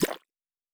Potion and Alchemy 05.wav